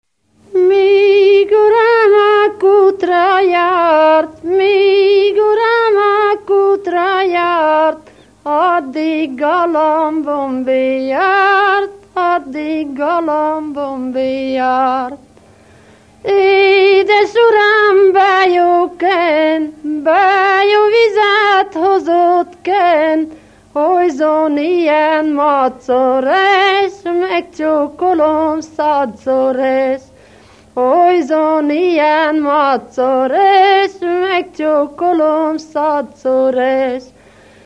Moldva és Bukovina - Moldva - Lészped
ének
Stílus: 7. Régies kisambitusú dallamok
Szótagszám: 7.7.7.7
Kadencia: b3 (b3) b3 1